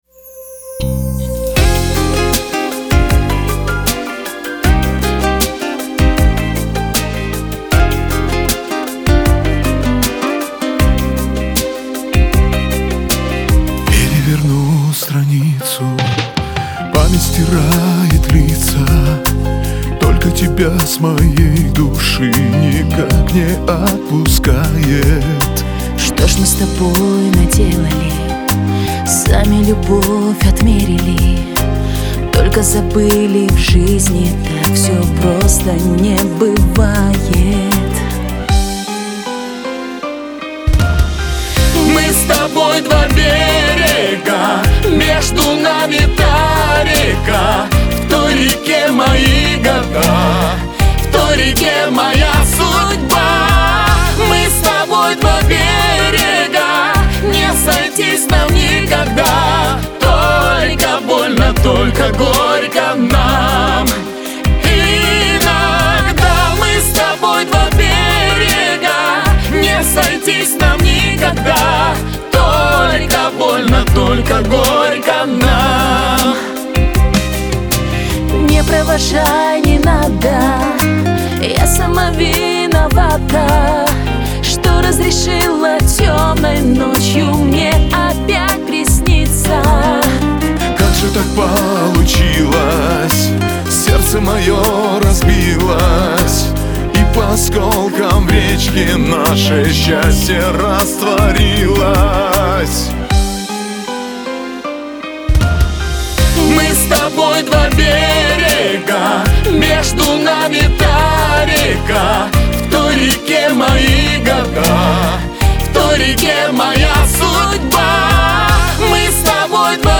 Лирика , Кавказ – поп
дуэт